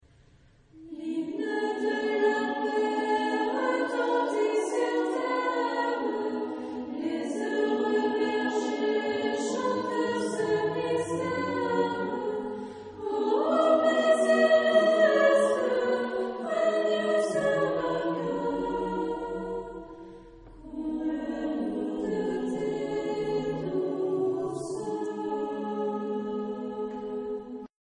Género/Estilo/Forma: Canción de Navidad ; Popular
Tipo de formación coral: AT O SAH O SATB  (4 voces Coro mixto O Coro a una voz )
Tonalidad : la menor